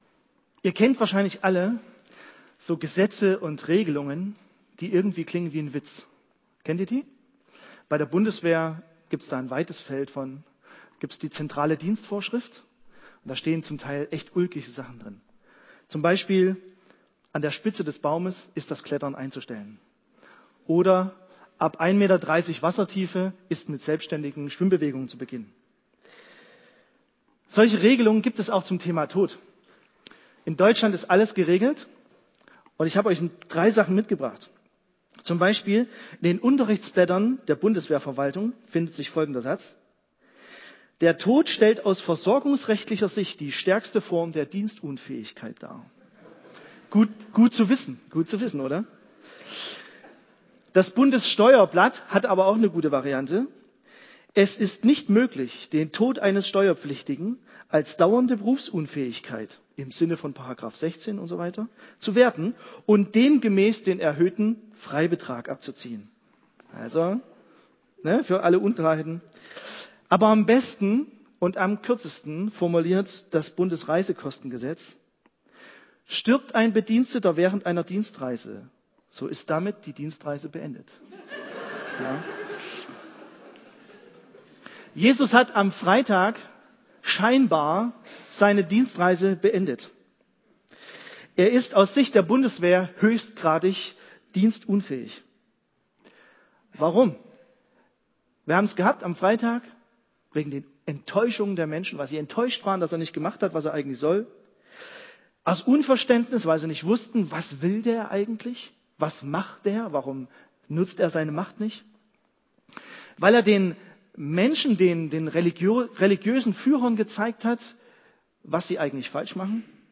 Grüße vom Ostergottesdienst - LKG Spremberg